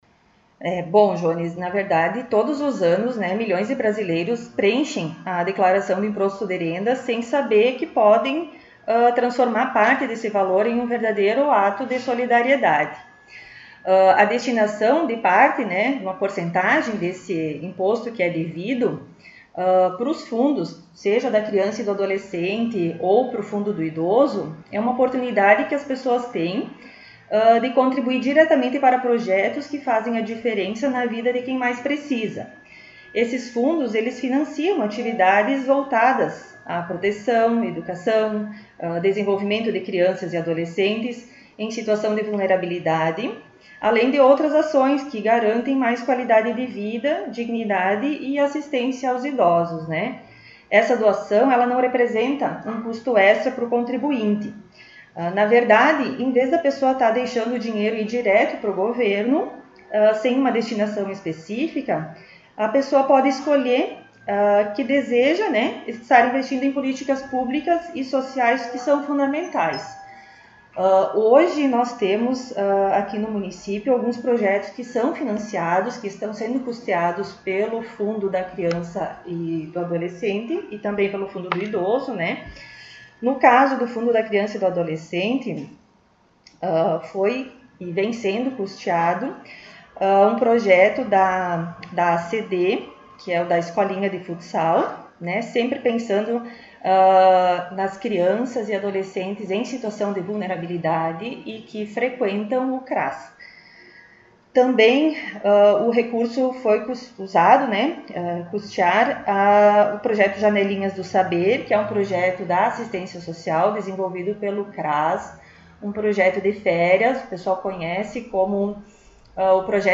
Diretora do CRAS concedeu entrevista